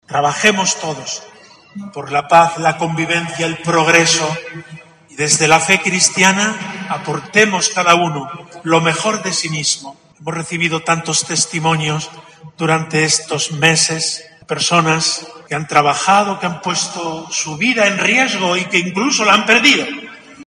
Un nutrido grupo de autoridades de la ciudad y fieles de la Diócesis de Córdoba se han congregado en la tarde de este miércoles en la Iglesia Catedral para celebrar la santa misa funeral en recuerdo a las víctimas mortales de la pandemia de covid-19.
El obispo de Córdoba, monseñor Demetrio Fernández, ha querido oficiar esta eucaristía tras vivir, como ha puesto de manifiesto en su homilía, unos días "muy amargos y muy duros", en los que "hemos visto la muerte muy de cerca".